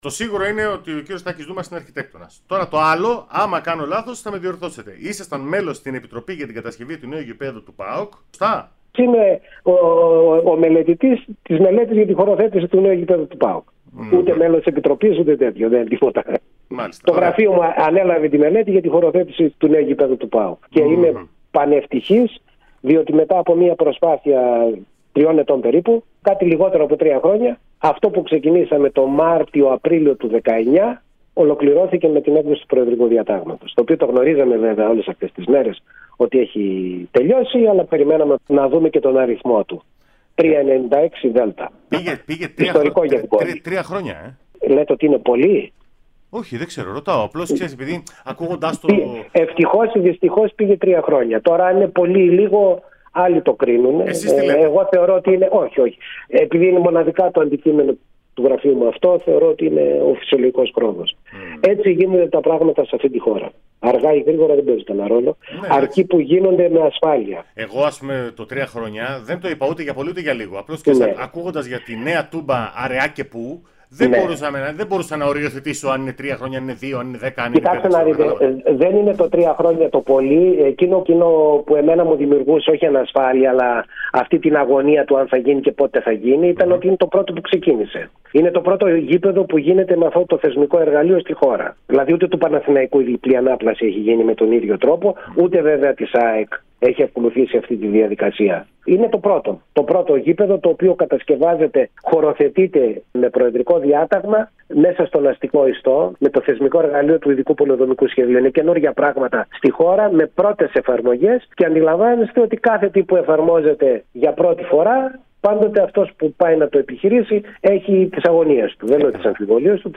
μίλησε στην ΕΡΑ ΣΠΟΡ και την εκπομπή «Δοκάρι και Μέσα»